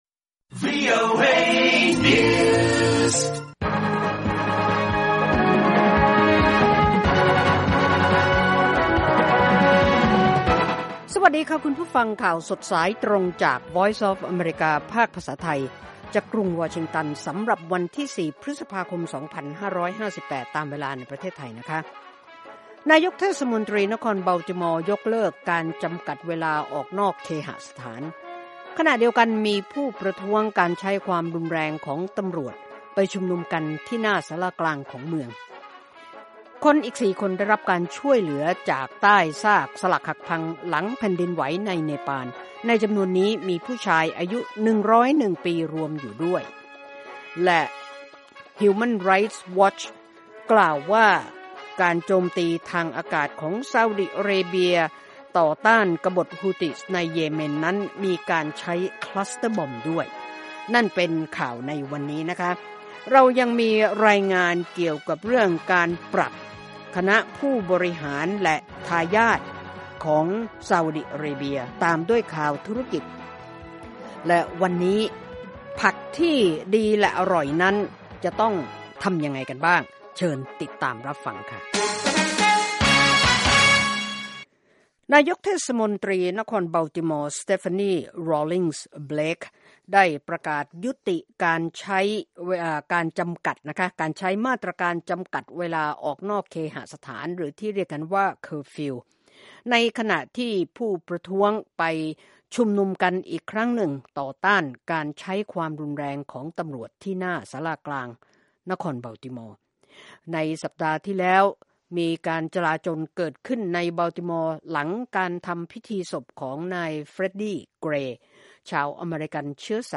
ข่าวสดสายตรงจากวีโอเอ ภาคภาษาไทย 8:30–9:00 น. วันจันทร์ที่ 4 พฤษภาคม 2558